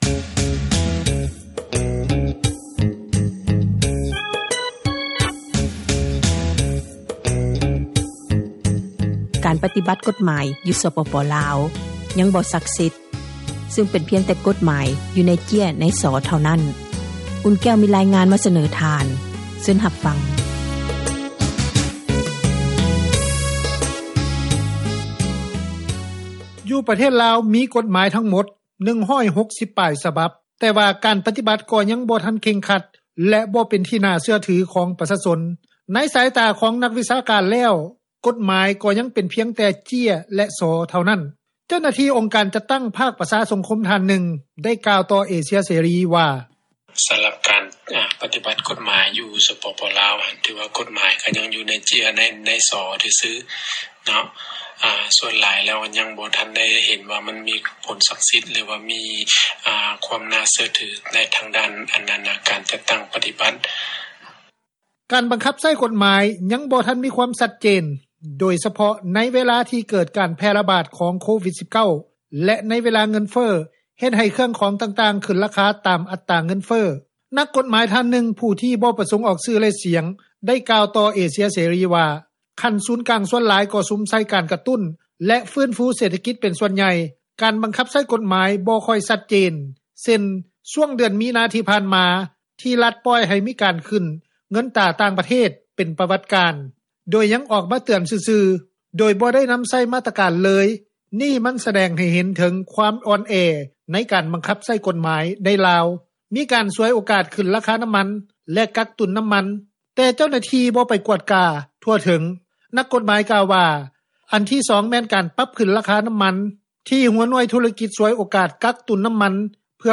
ນັກກົດໝາຍ ທ່ານນຶ່ງ ຜູ້ທີ່ບໍ່ປະສົງອອກຊື່ ແລະ ສຽງ ໄດ້ກ່າວຕໍ່ວິທຍຸເອເຊັຽເສຣີ ວ່າ: ຂັ້ນສູນກາງສ່ວນຫລາຍກໍ່ສຸມໃສ່ການກະຕຸ້ນ ແລະຟື້ນຟູເສຖກິຈເປັນສ່ວນໃຫຍ່, ການບັງຄັບໃຊ້ກົດໝາຍບໍ່ຄ່ອຍຊັດເຈນເຊັ່ນ ຊ້ວງເດືອນມີນາທີ່ຜ່ານມາທີ່ ຣັຖປ່ອຍໃຫ້ມີການປັ່ນຂຶ້ນ ເງິນຕຣາຕ່າງປະເທດເປັນປວັດການ ໂດຍຍັງອອກມາເຕືອນຊື່ໆ ໂດຍບໍ່ໄດ້ນຳໃຊ້ມາຕການ ເລີຍນີ້ມັນສະແດງໃຫ້ເຫັນເຖິງ ຄວາມອ່ອນແອໃນການບັງຄັບ ໃຊ້ກົດໝາຍໃນລາວ, ມີການສວຍໂອກາດຂຶ້ນລາຄານ້ຳມັນ ແລະ ກັກຕຸນນ້ຳມັນ ແຕ່ເຈົ້າໜ້າທີ່ ບໍ່ໄປກວດກາທົ່ວເຖິງ.
ປະຊາຊົນອີກທ່ານນຶ່ງ ກໍໃຫ້ສັມພາດວ່າ ບໍ່ມີຄວາມເຊື້ອໝັ້ນ ໃນການຕິບັດກົດໝາຍຢູ່ລາວ ເຊັ່ນກັນ.